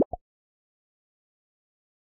Refactor upgrade logic to include sound effects on purchases and upgrades, improving overall gameplay immersion. 2025-03-30 14:24:53 -04:00 52 KiB Raw History